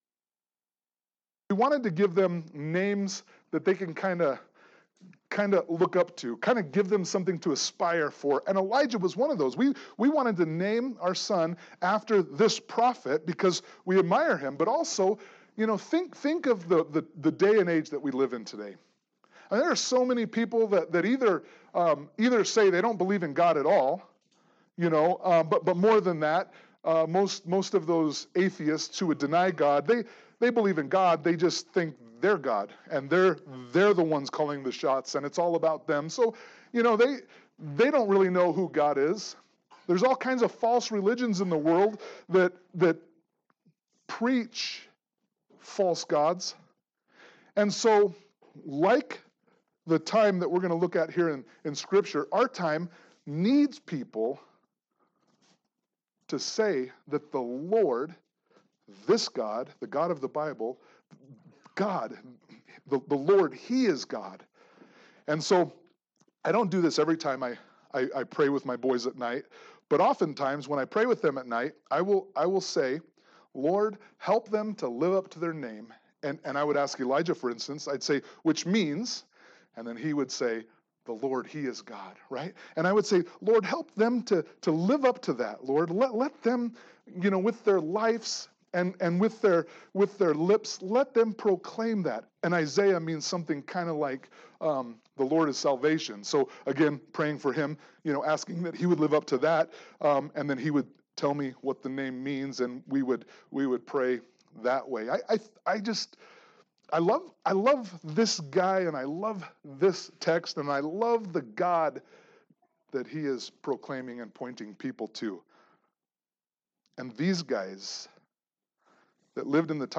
Note: the beginning of the MP3 Audio is cut off.
1 Kings 18 Service Type: Sunday Morning Worship Note